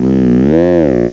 cry_not_bewear.aif